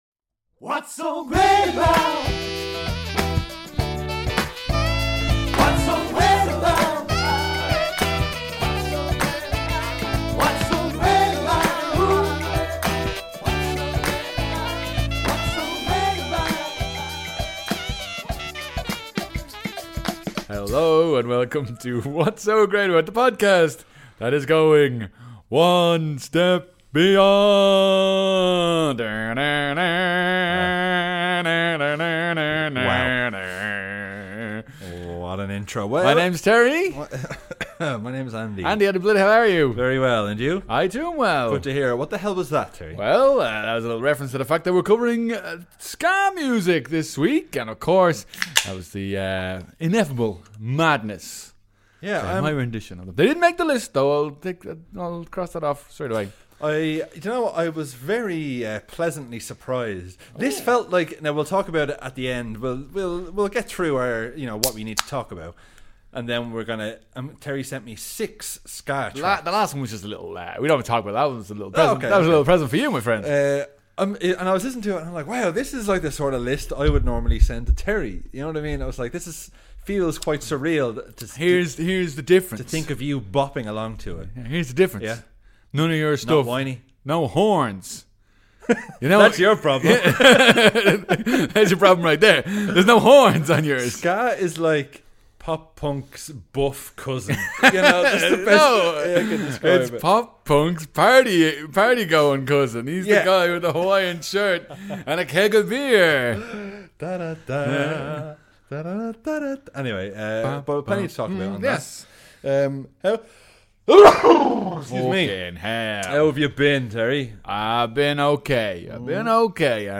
We chat about Cruise climbing the Burj Khalifa, Simon Pegg being brilliant, and just HOW MUCH did Ving Rhames get paid for his tiny screen time! We also chat about TV licenses, falling asleep while wathcing TV, and we do some KILLER impressions!